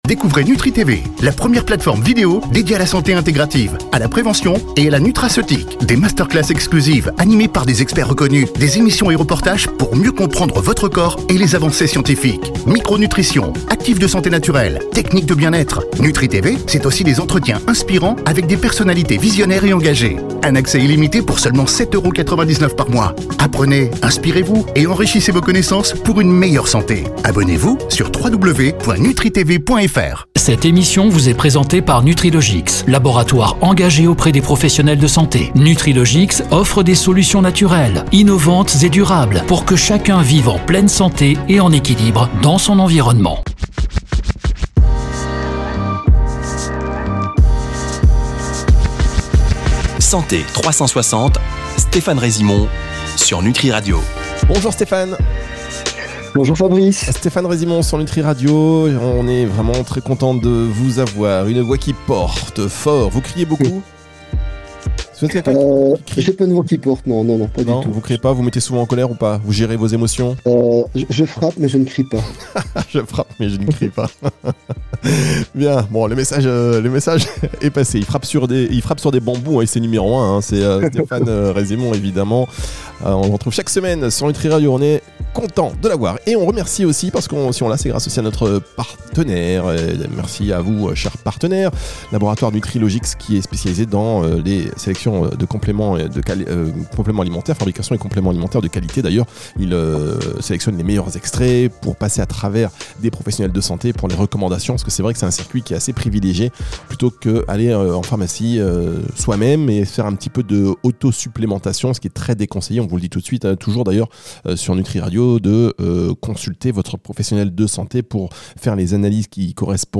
Un échange sans tabou, concret, où la médecine fonctionnelle prend tout son sens.